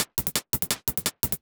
UHH_ElectroHatC_170-05.wav